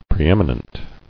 [pre·em·i·nent]